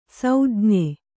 ♪ saujñe